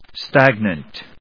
音節stag・nant 発音記号・読み方
/stˈægnənt(米国英語)/